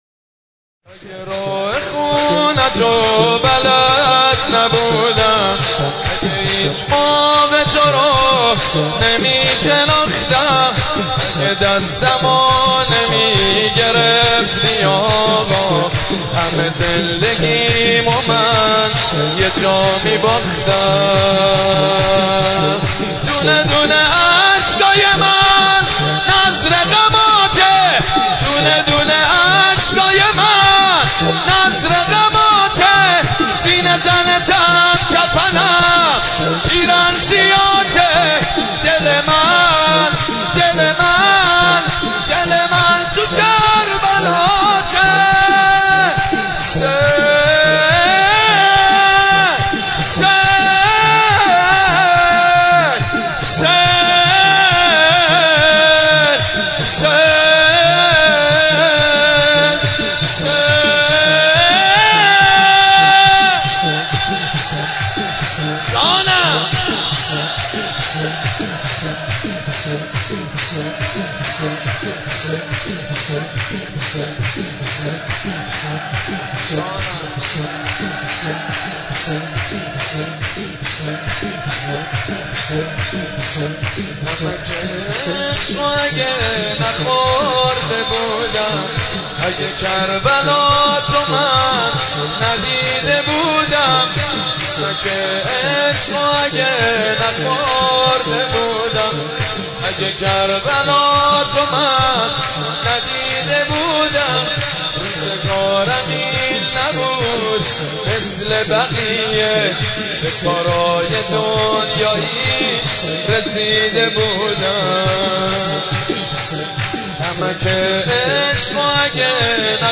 «میلاد امام رضا 1391» شور: اگه که راه خونتو بلد نبود